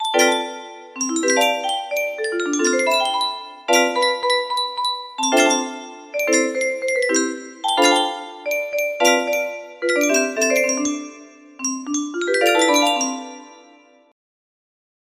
infinite love music box melody